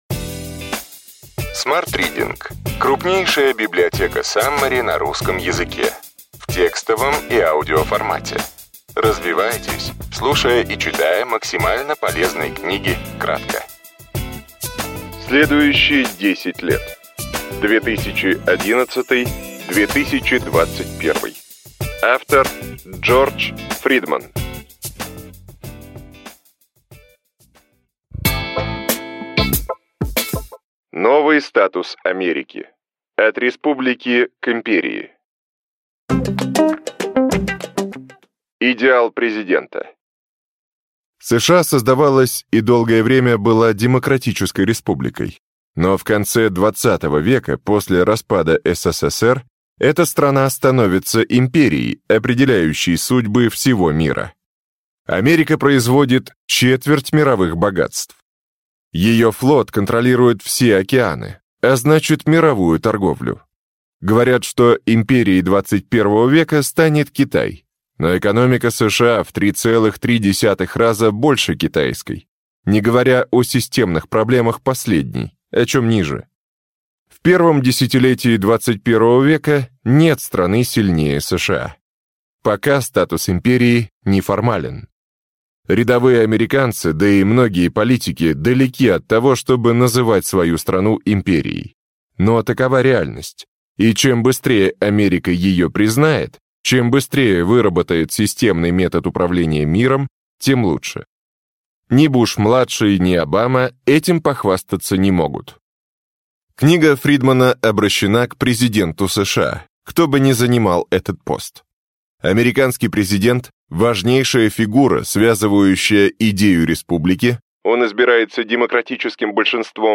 Аудиокнига Ключевые идеи книги: Следующие 10 лет: 2011–2021.